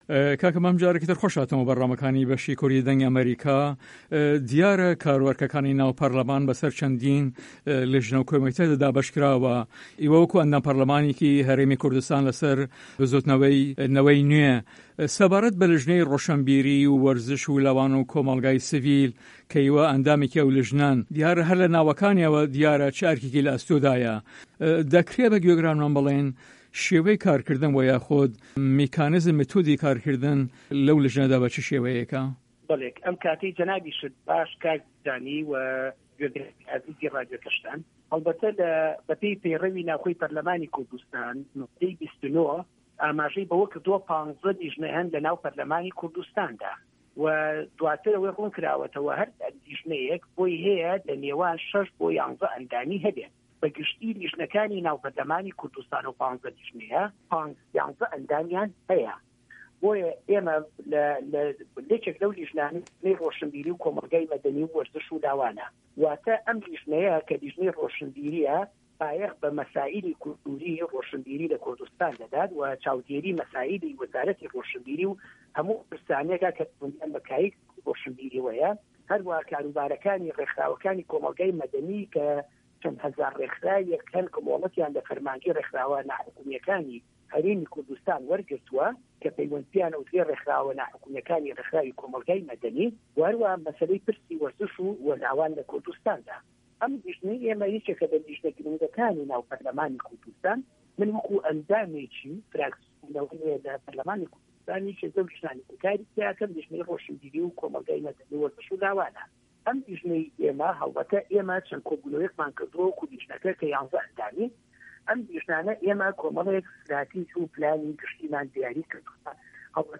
Interview with Mam Qanie